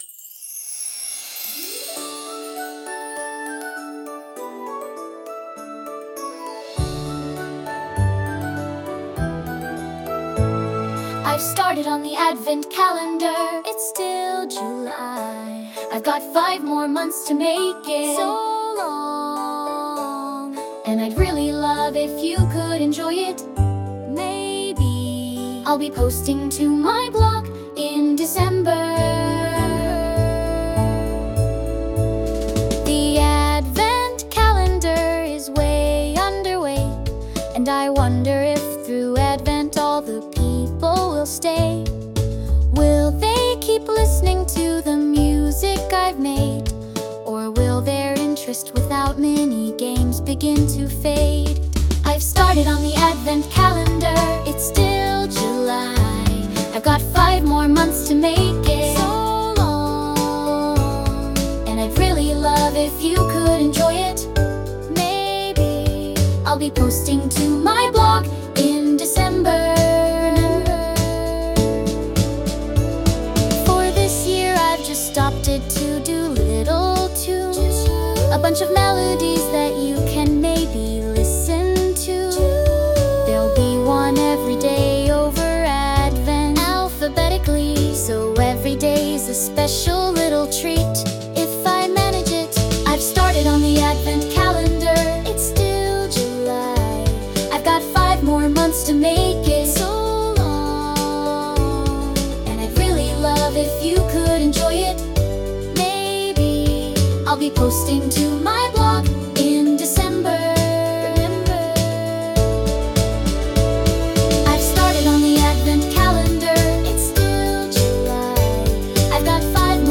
Sound Imported : Recorded Sleighbells
Sung by Suno